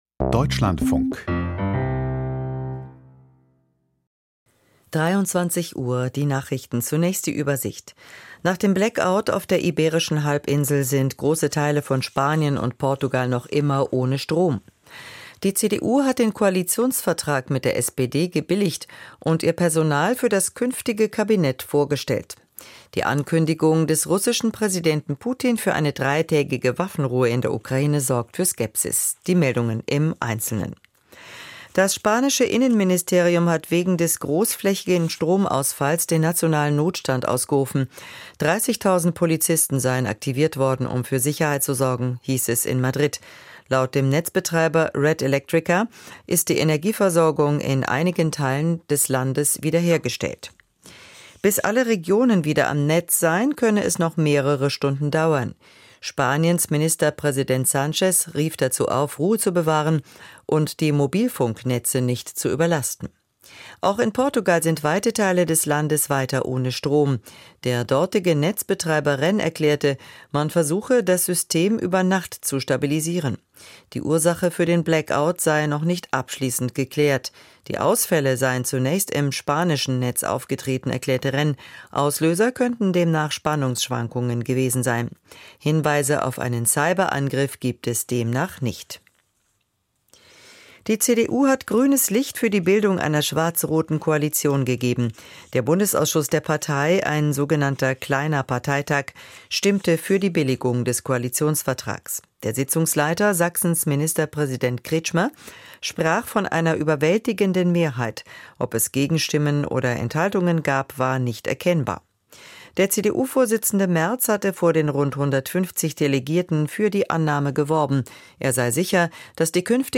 Die Deutschlandfunk-Nachrichten vom 28.04.2025, 23:00 Uhr